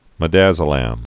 (mĭ-dăzə-lăm)